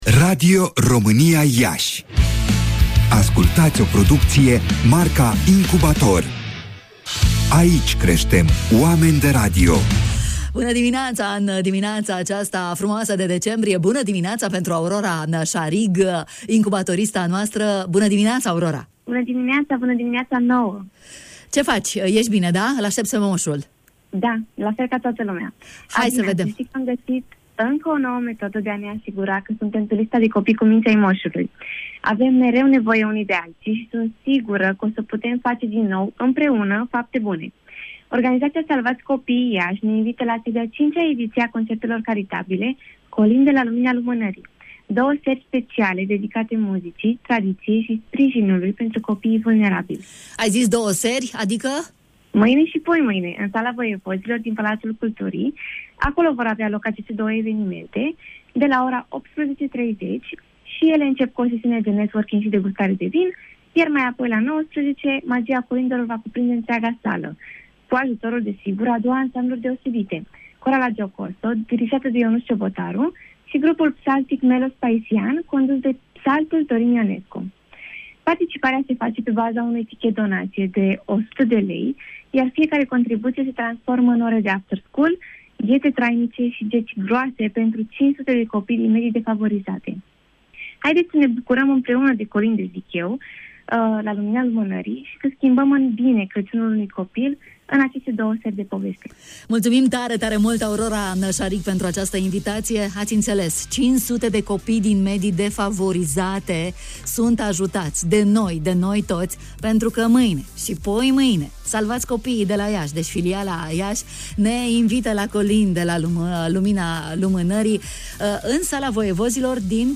reporter Incubator în „Bună dimineața”